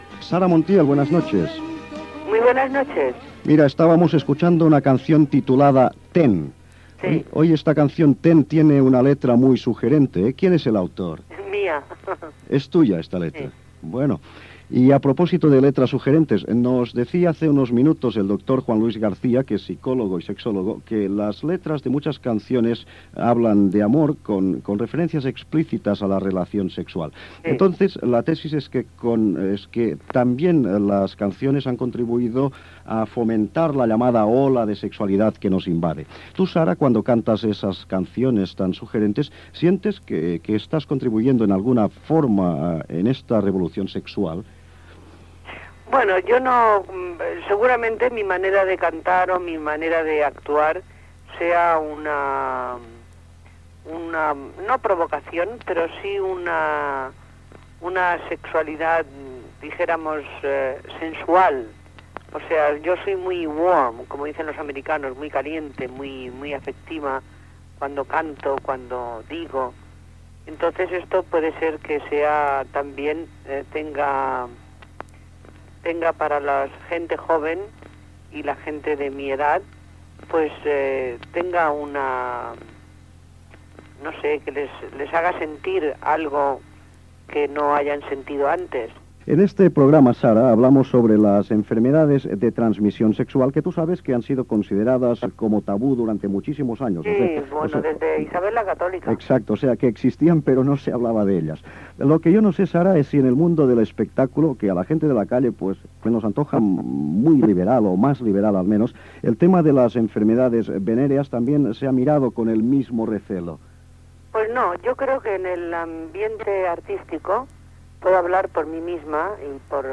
Entrevista a l'actriu i cantant Sara Montiel en un programa sobre higiene sexual
Divulgació